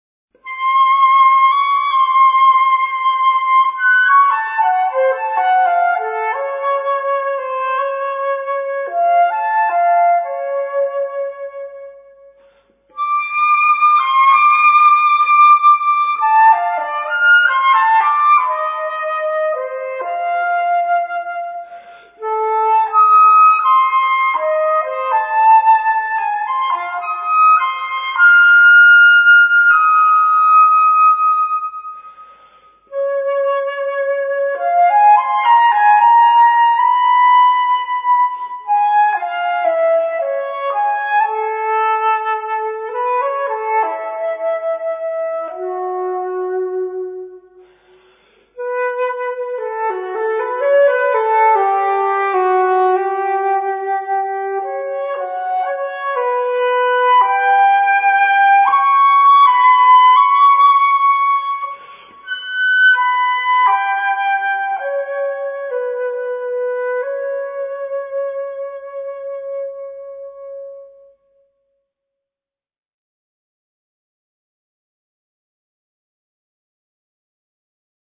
Zwölf Rubato-Stückchen für Flöte solo